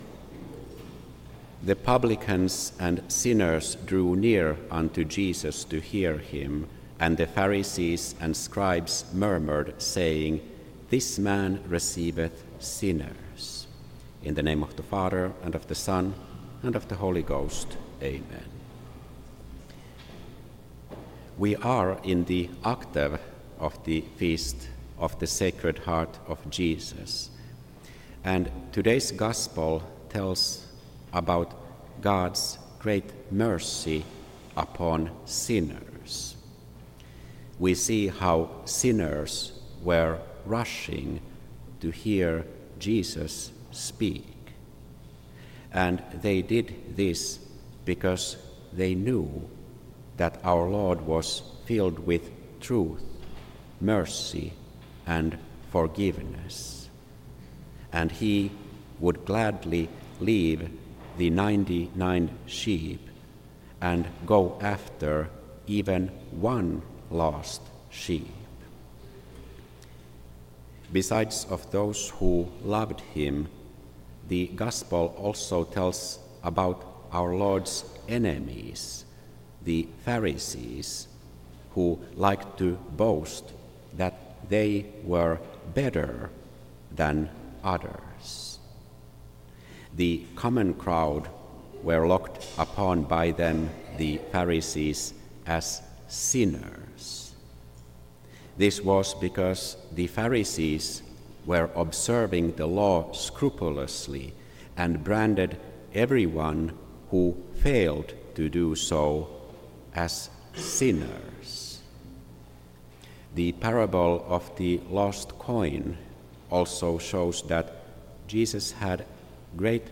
Make our Hearts Like Unto Thine Download the Sermon Within the Octave of the Sacred Heart Preacher